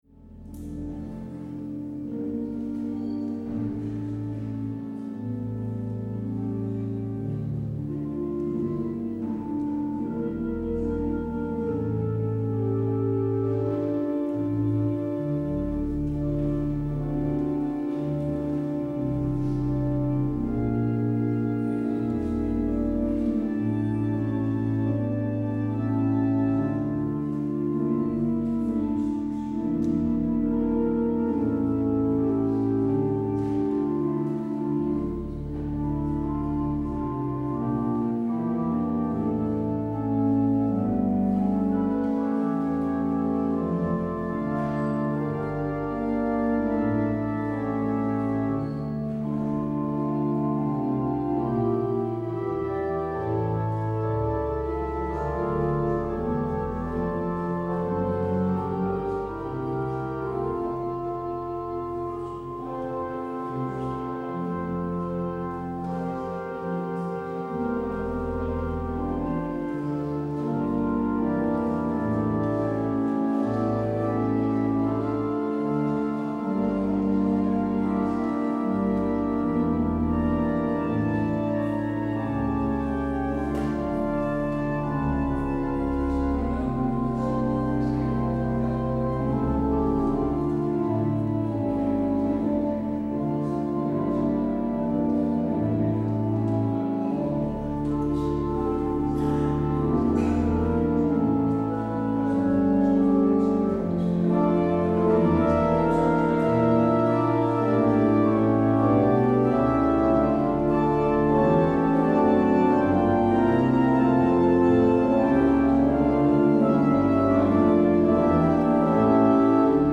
Jezus wist dat zijn tijd gekomen was en dat Hij uit de wereld terug zou keren naar de Vader Het openingslied is: Psalm 65: 1 en 2.